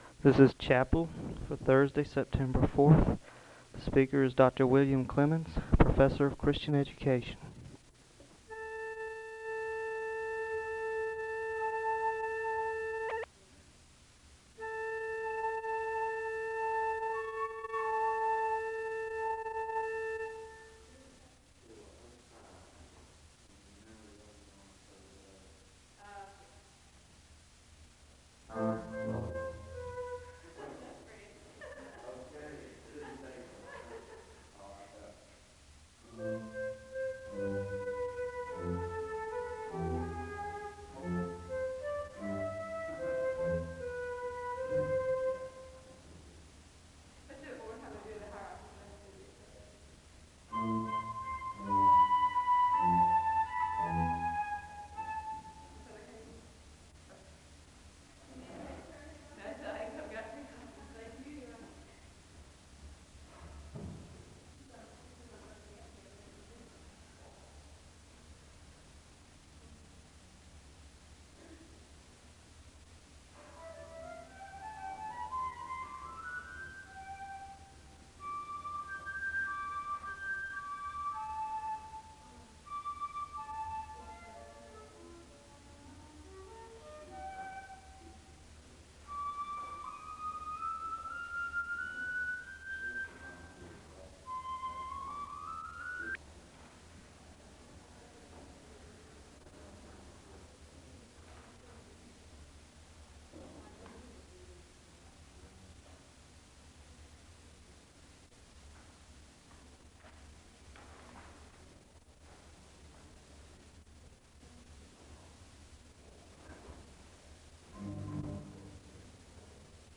The service begins with organ music (0:00-7:04). The choir sings a song of worship (7:05-9:47). There is a responsive reading (9:48-11:25). The congregation joins in singing “Be Thou My Vision” (11:26-14:15).